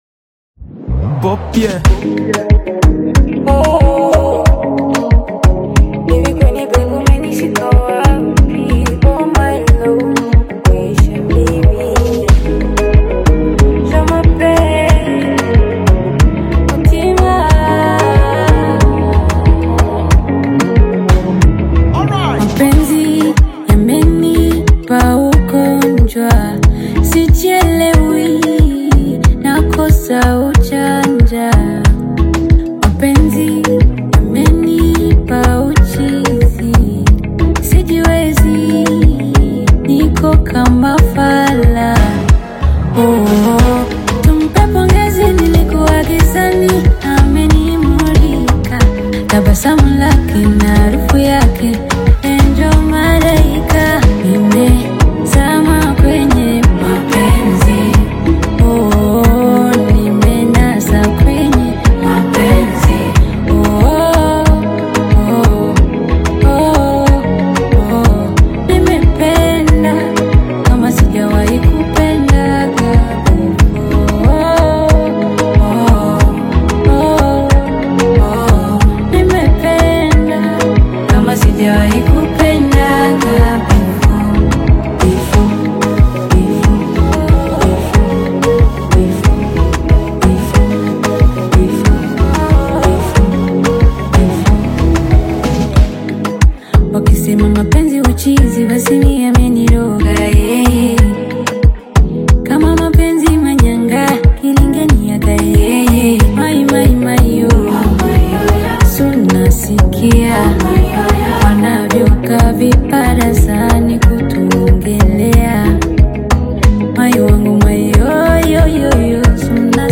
Tanzanian Bongo Flava/Afro-Pop single